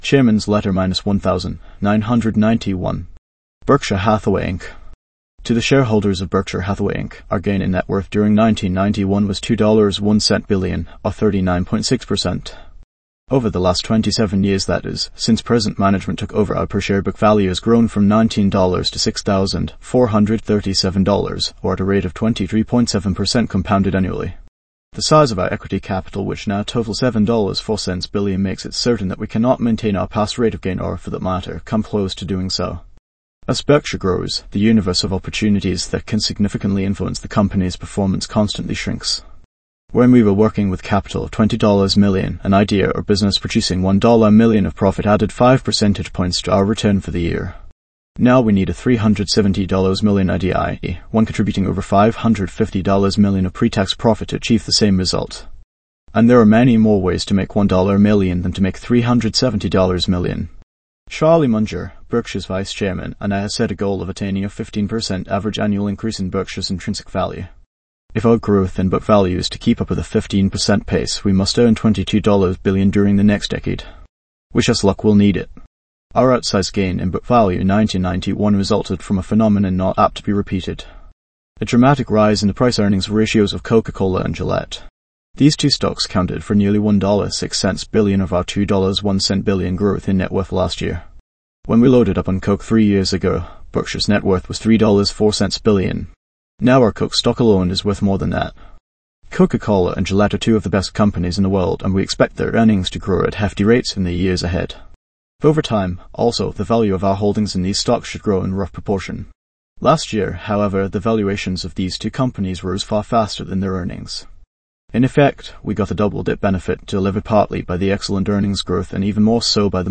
value-investors-tts
a voice model for